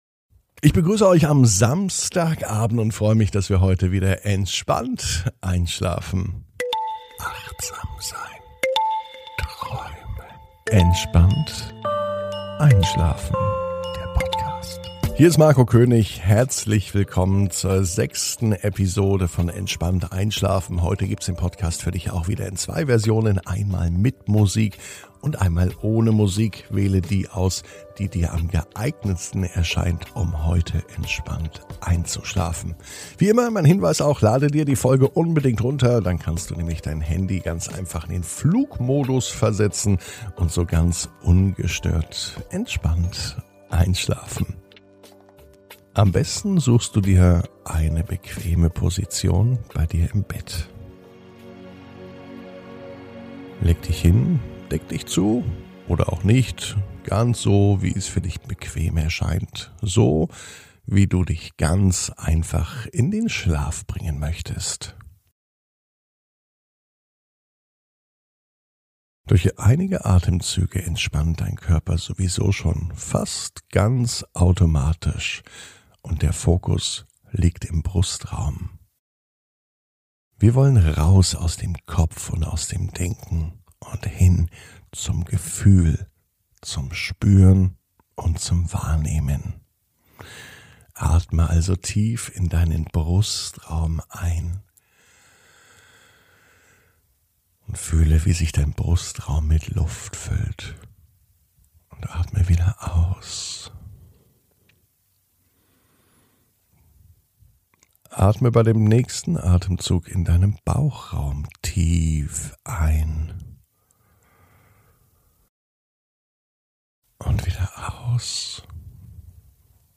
(ohne Musik) Entspannt einschlafen am Samstag, 24.04.21 ~ Entspannt einschlafen - Meditation & Achtsamkeit für die Nacht Podcast